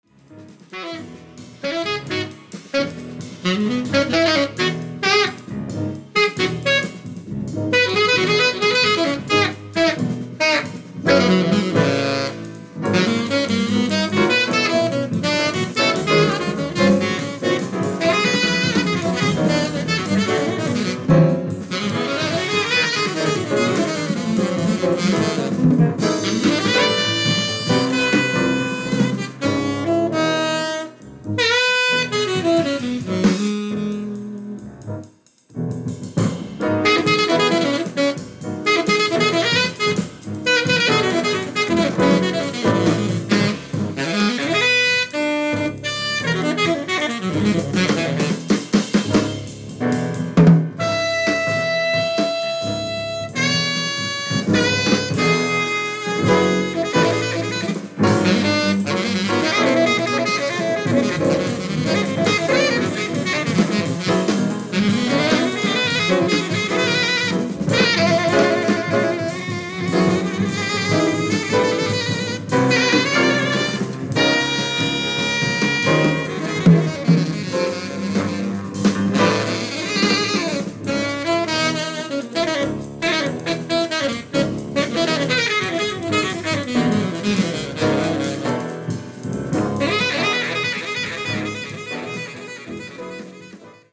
Drums
Bass
Piano
Saxophone, Clarinet